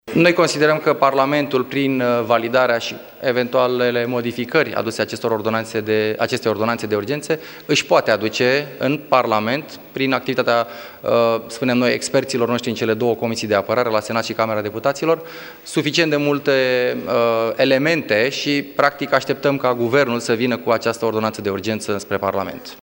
Președintele UNPR, Valeriu Steriu, a declarat, după întâlnirea avută cu președintele Klaus Iohannis că formațiunea pe care o conduce așteaptă ca Guvernul să vină cu o ordonanță în Parlament: